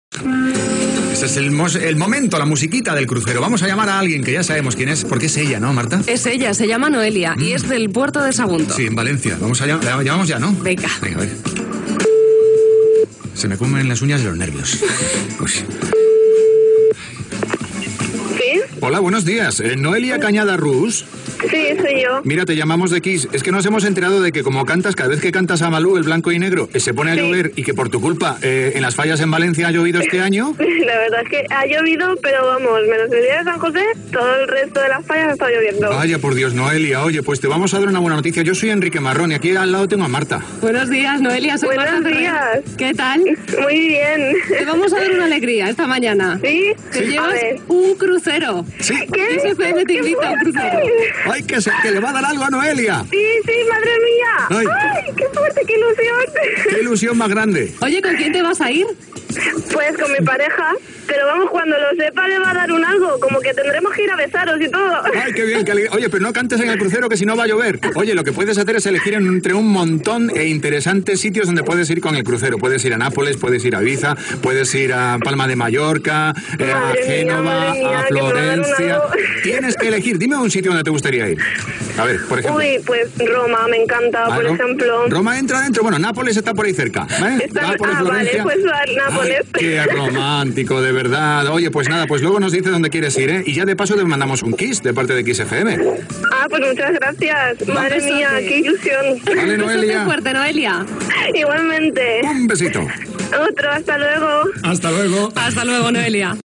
Trucada a una oïdora que guanya un creuer pel Mediterrani
Entreteniment